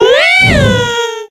adds gen 7 icons & cries